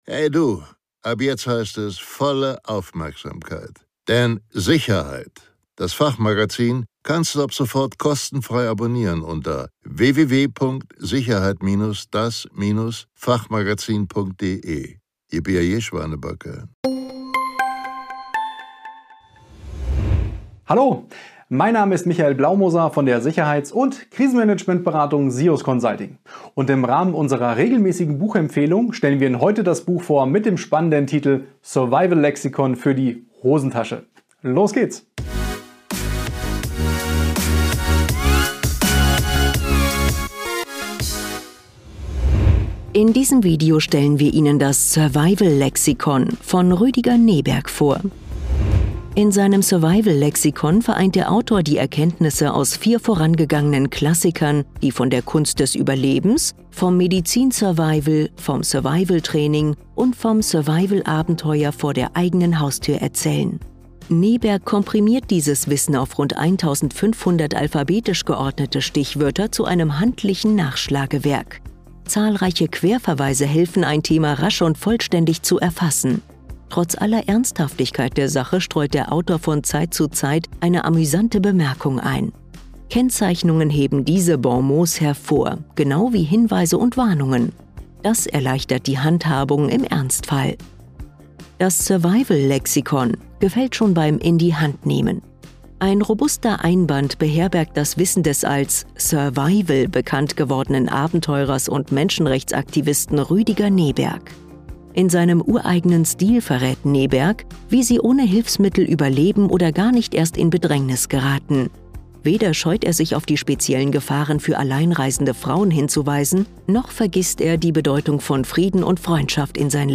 In dem wöchentlichen Podcast von „SIUS Consulting: Sicherheitsberatung und Krisenmanagement“ finden Sie die Tonspuren aller Videos aus dem YouTube-Kanal von SIUS Consulting.